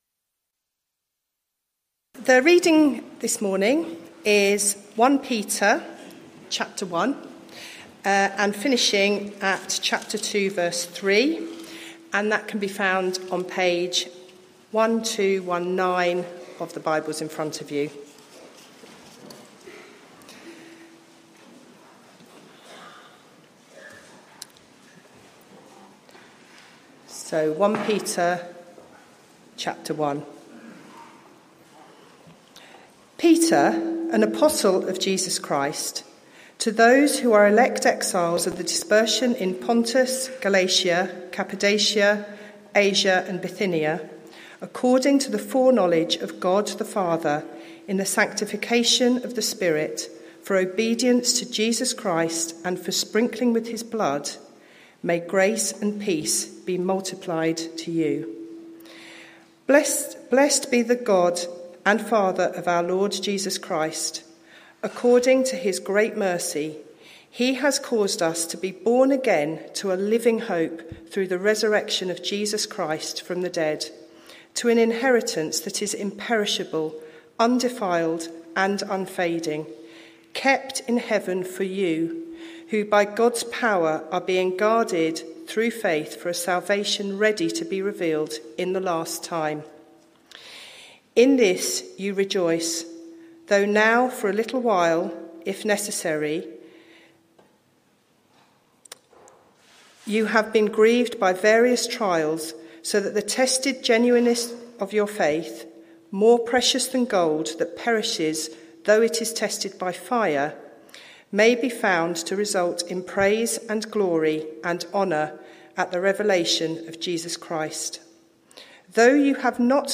Sermon - Audio Only Search media library...